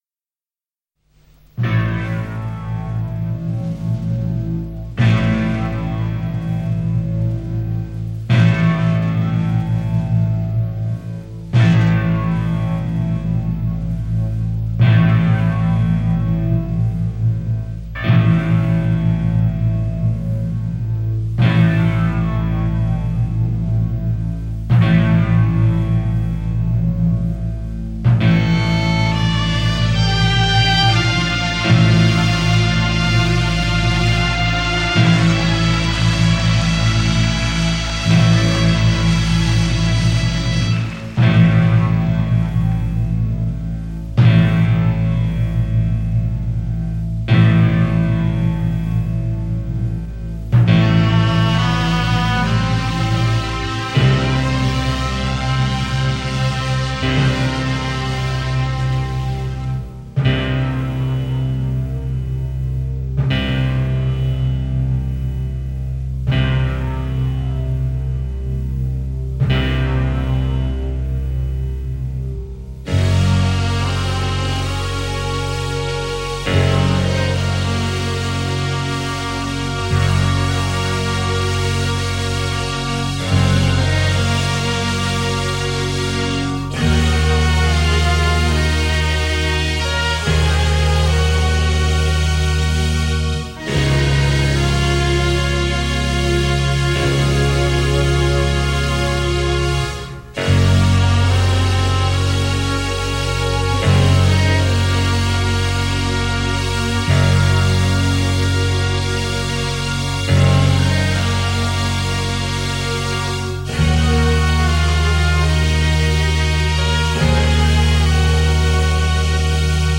in concert at The Budokan, Tokyo